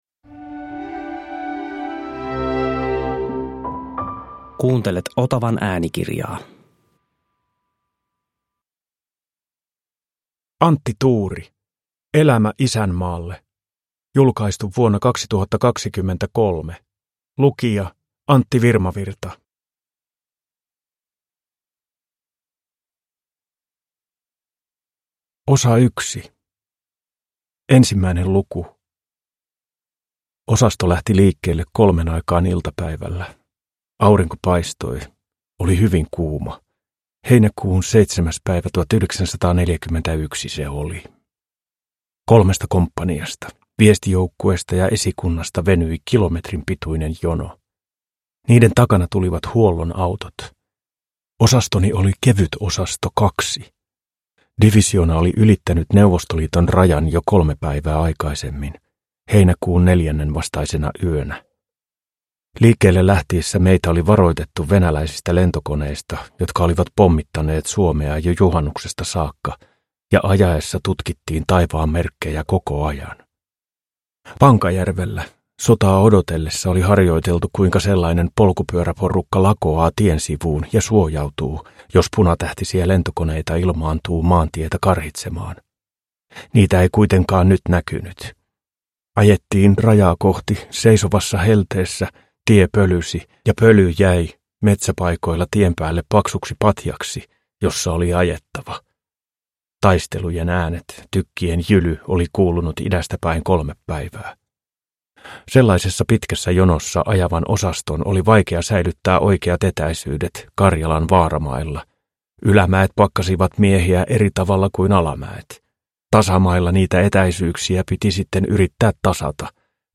Elämä isänmaalle – Ljudbok – Laddas ner